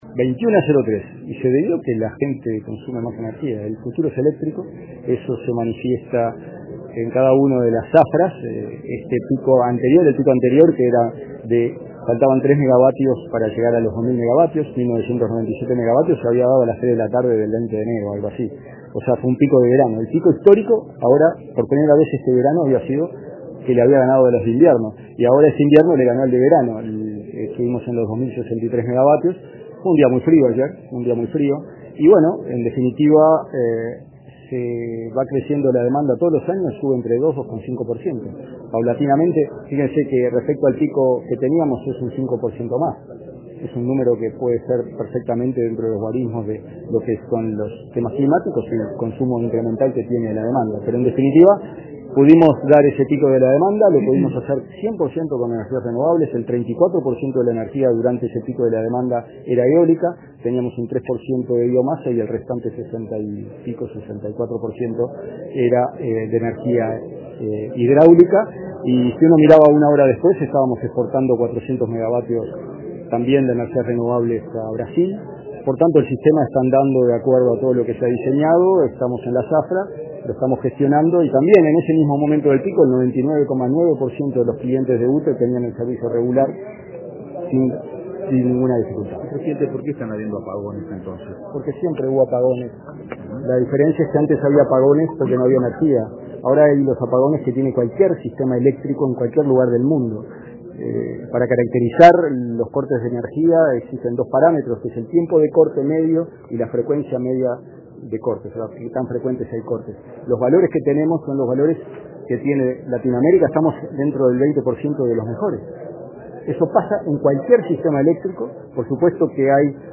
El presidente de UTE, Gonzalo Casaravilla, destacó que se cubrió el 100 % con energía renovable.